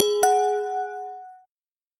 Мужской голос контрольная точка